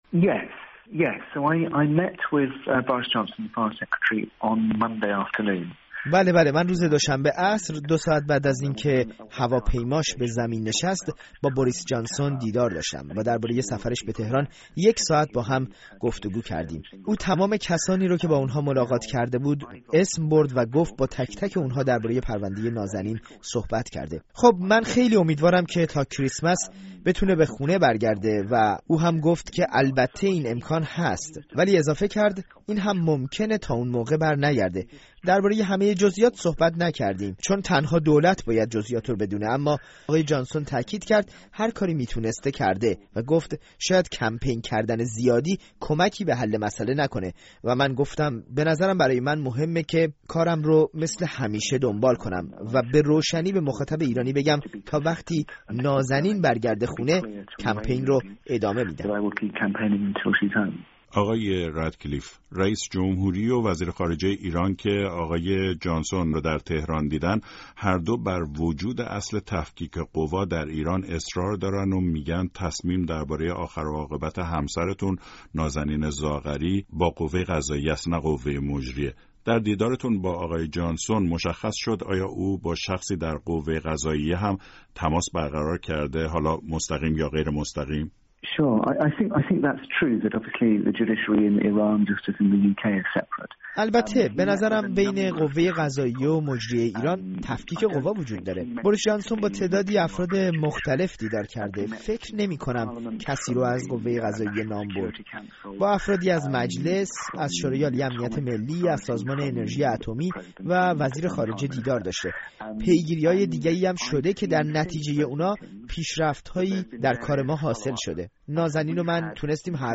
رتکلیف در گفت‌وگو با رادیو فردا: رویای بازگشت نازنین زاغری تا کریسمس زنده است